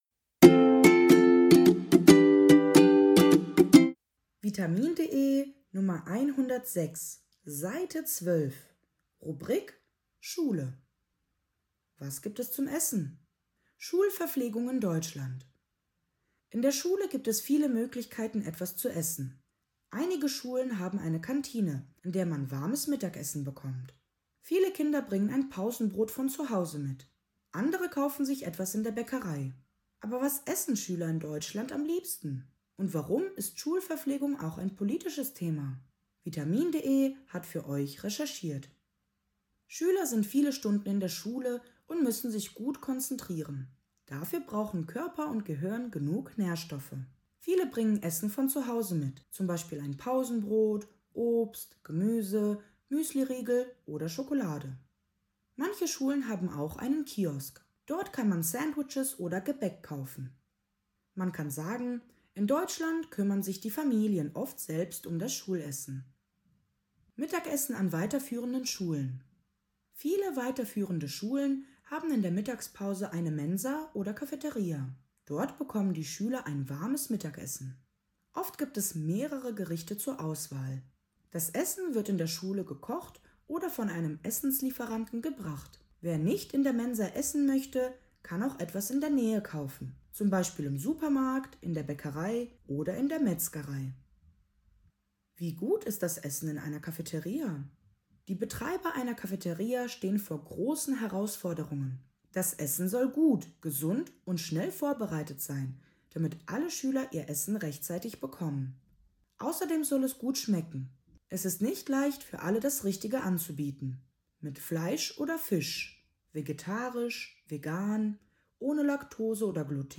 Audiodatei (Hörversion) zum Text